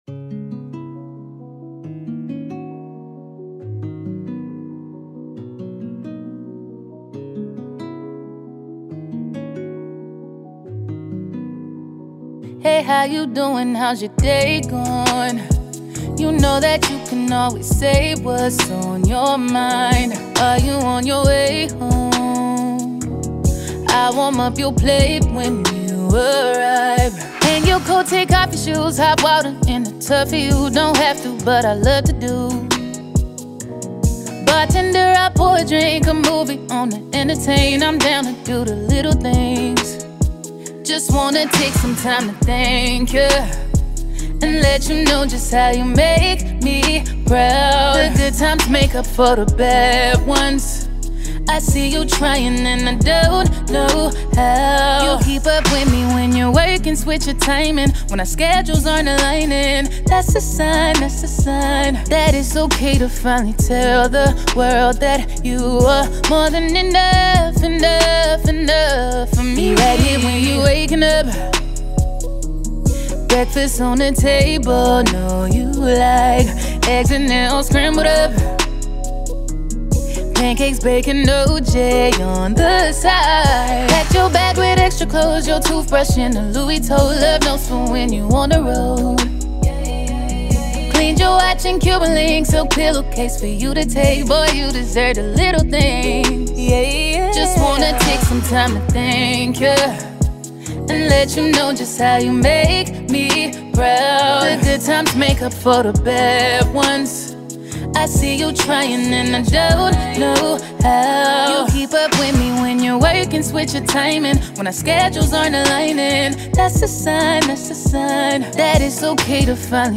R&B
soulful ballad
soothing vocals